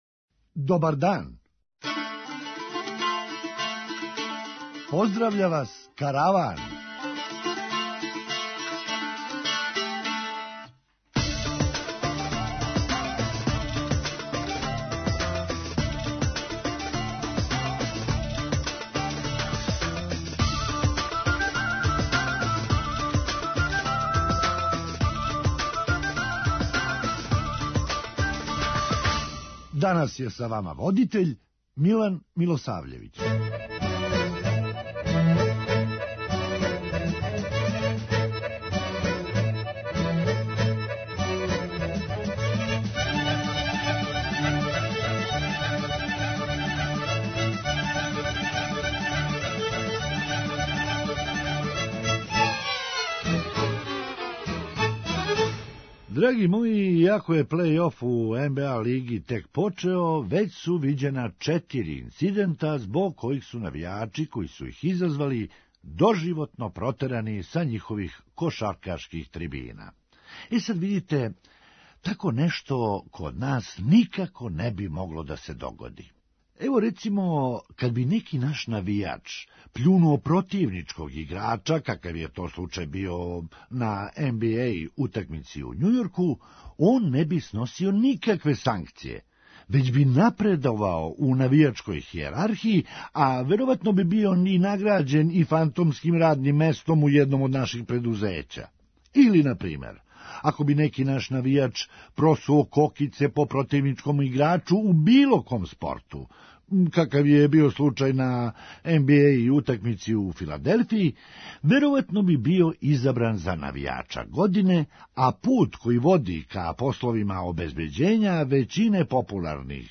Хумористичка емисија
То значи да ће ММФ моћи у сваком тренутку да се Влади Србије обрати за неки савет. преузми : 9.75 MB Караван Autor: Забавна редакција Радио Бeограда 1 Караван се креће ка својој дестинацији већ више од 50 година, увек добро натоварен актуелним хумором и изворним народним песмама.